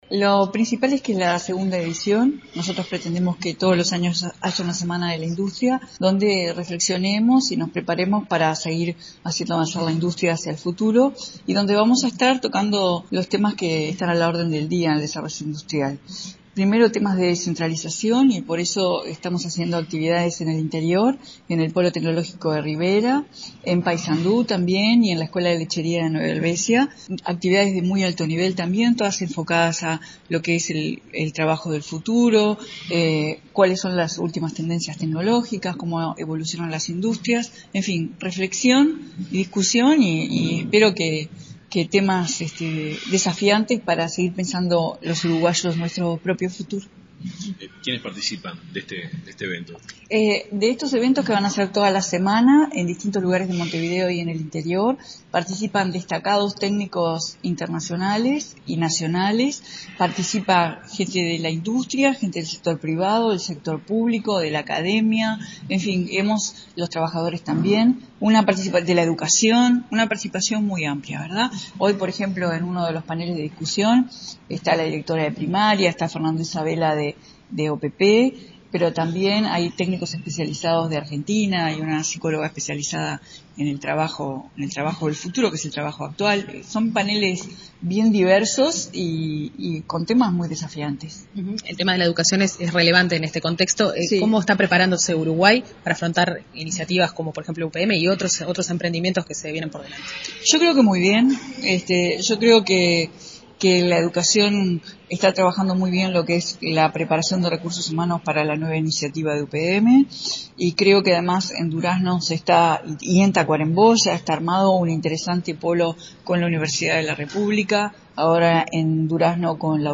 “Es una jornada para la reflexión y la discusión, y para tratar temas desafiantes para seguir pensando nuestro futuro”, afirmó la ministra Carolina Cosse, en una jornada sobre Educación para la industria, en el marco de la Semana de la Industria. Aseguró que Uruguay prepara “muy bien” a sus trabajadores para emprendimientos como UPM porque apuesta a la tecnificación y a la especialización de la producción.